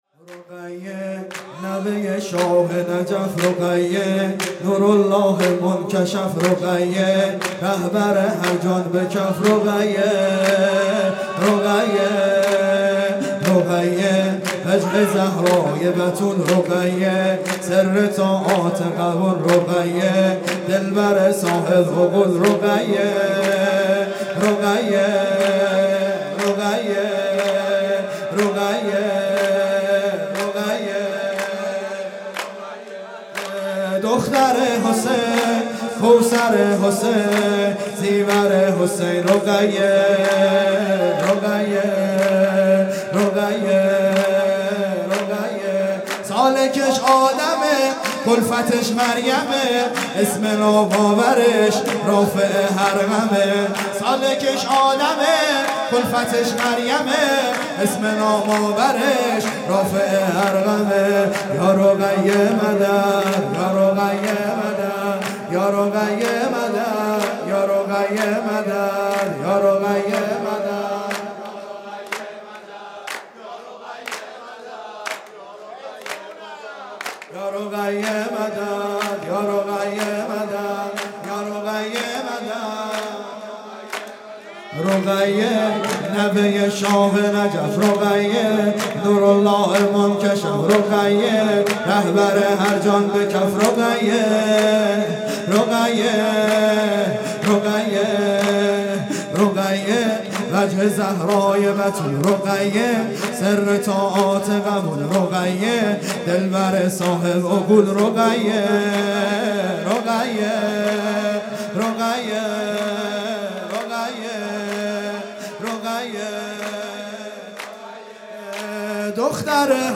سرود
ولادت حضرت رقیه (س) | ۲۶ اردیبهشت ۹۶